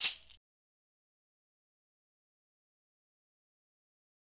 Perc [Sota].wav